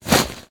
inv_belt.ogg